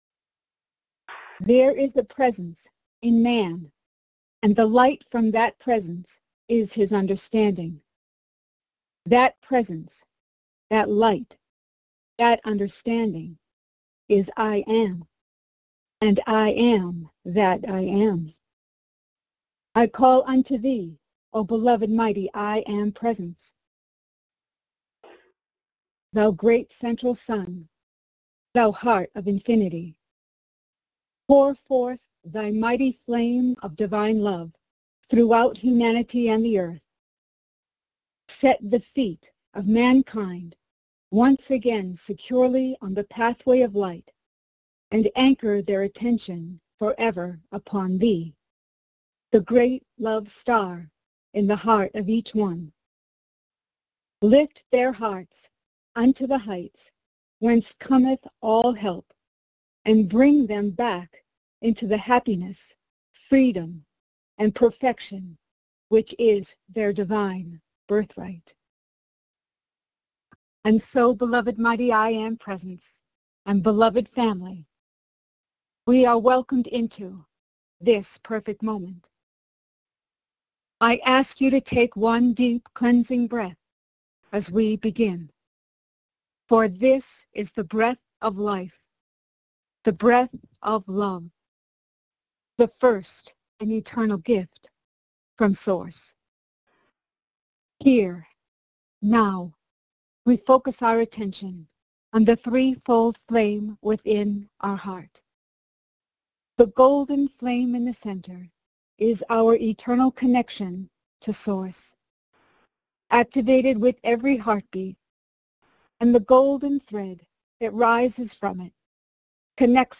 Join in and follow along in group meditation with Lord Sananda